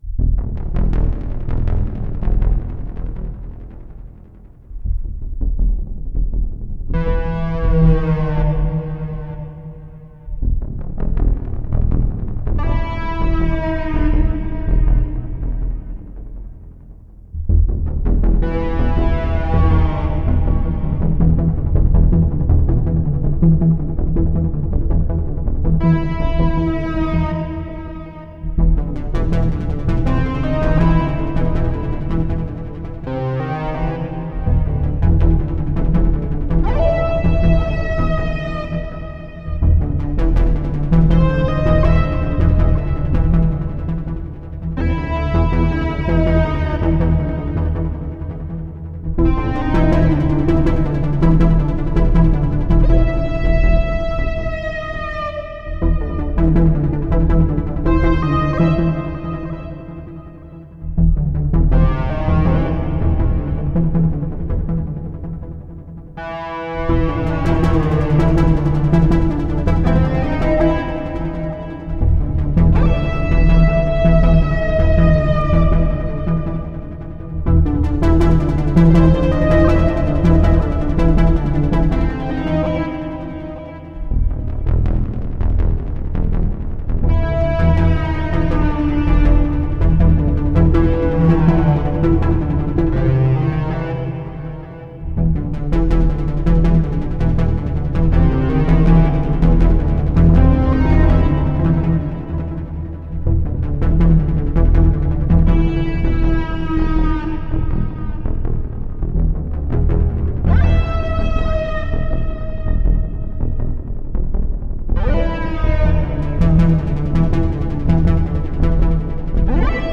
Electro Film Musik.